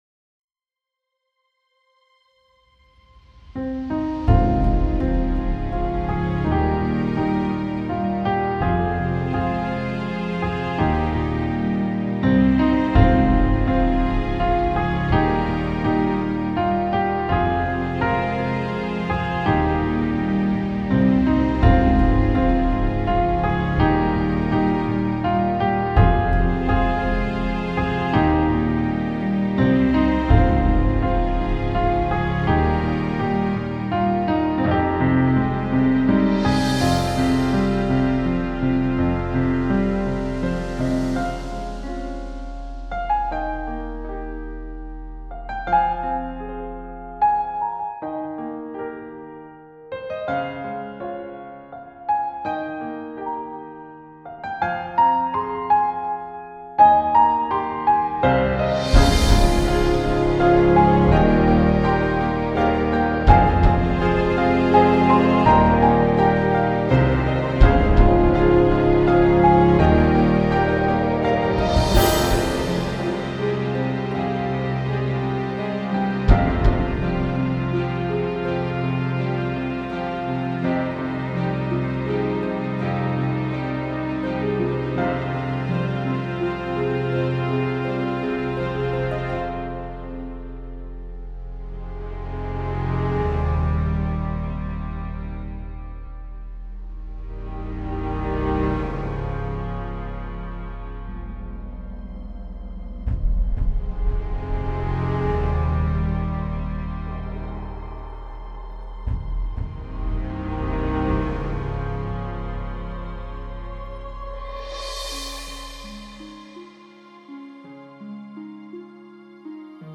Beautiful and emotional orchestra music.